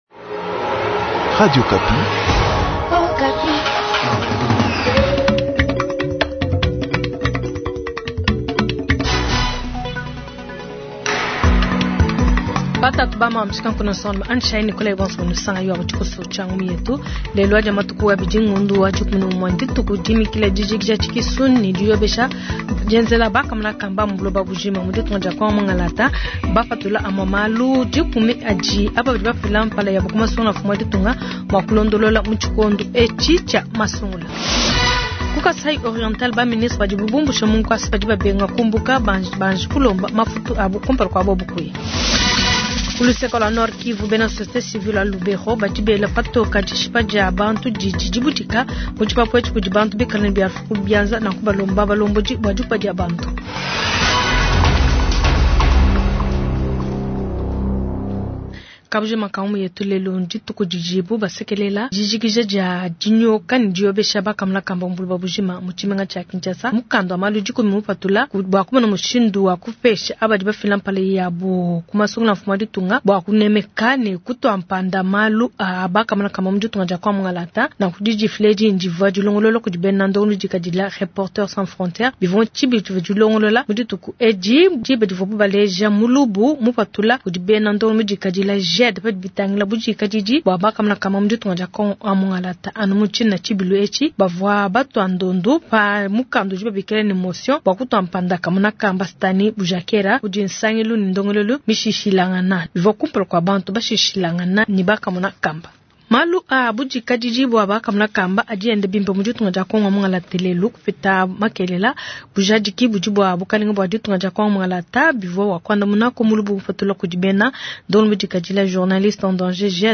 • BUNIA : Reportage sur le déploiement du matériel électoral